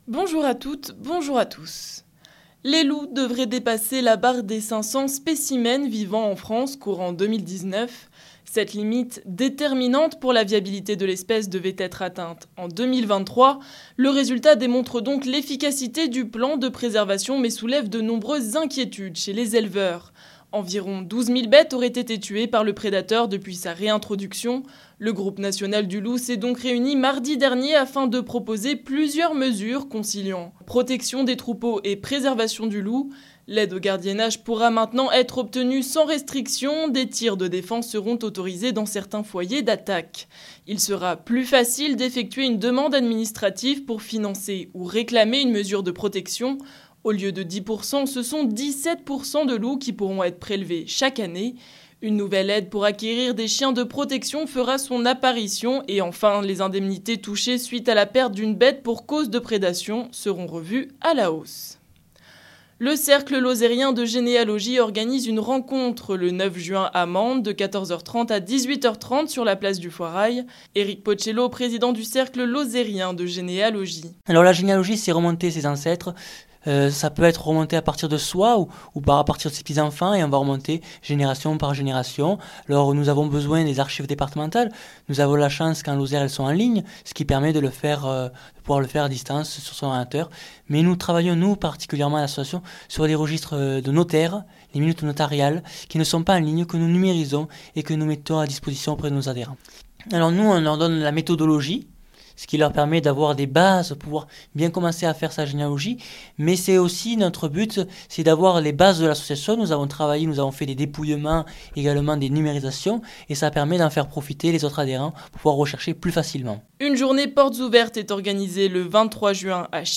Les informations locales